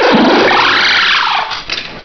pokeemerald / sound / direct_sound_samples / cries / klinklang.aif
-Replaced the Gen. 1 to 3 cries with BW2 rips.